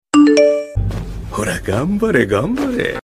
Toque de notificação da voz de Sukuna
Categoria: Toques
toque-de-notificacao-da-voz-de-sukuna-pt-www_tiengdong_com.mp3